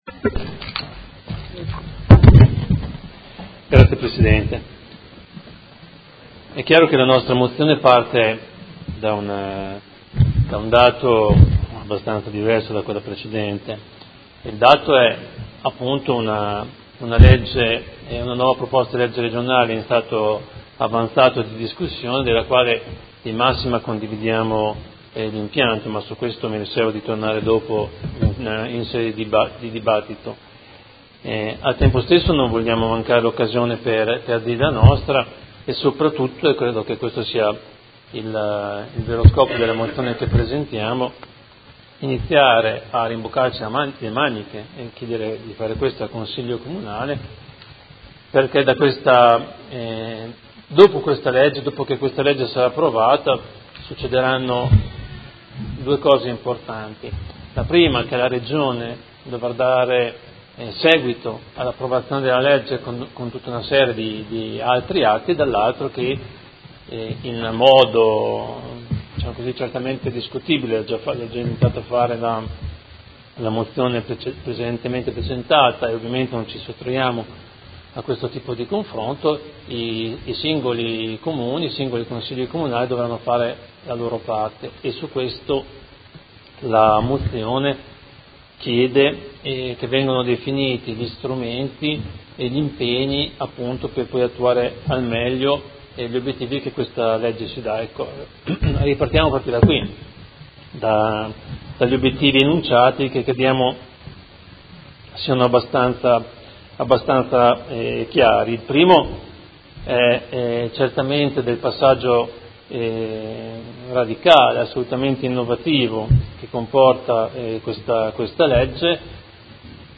Fabio Poggi — Sito Audio Consiglio Comunale
Seduta del 19/10/2017 Ordine del Giorno n. 157326. La nuova legge urbanistica regionale, considerazione e proposte.